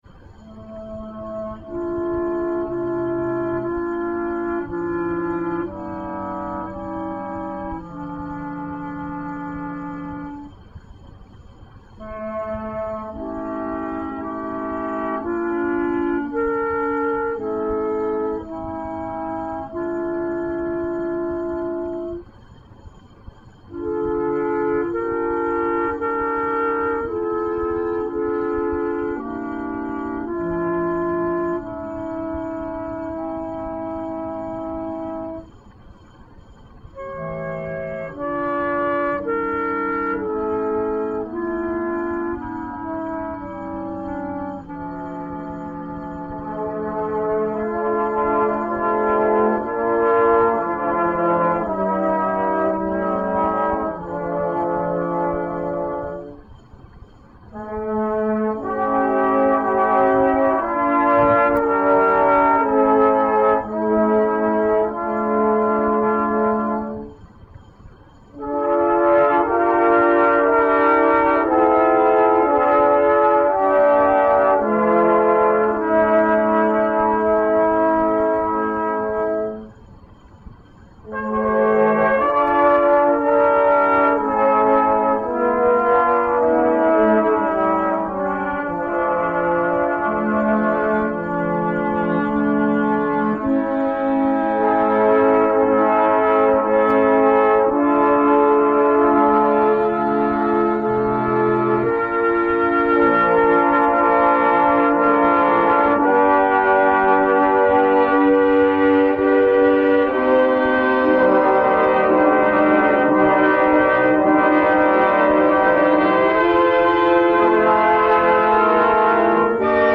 A large-scale work written for full symphonic band.